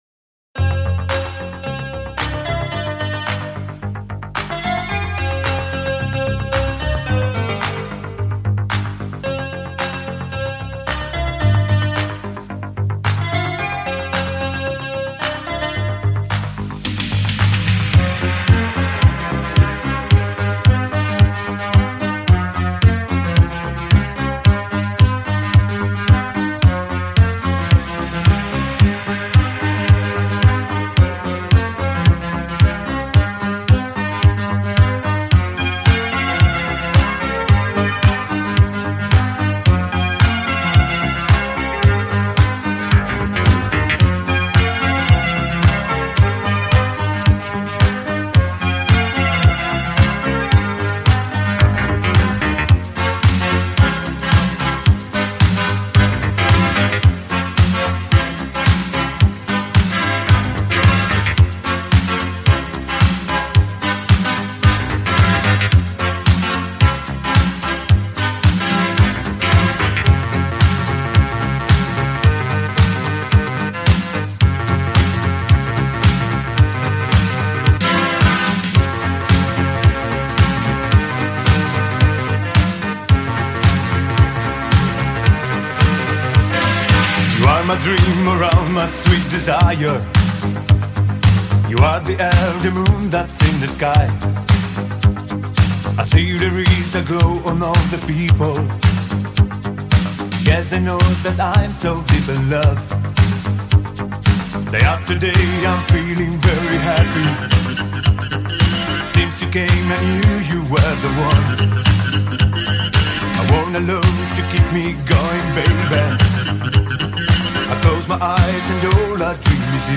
#80's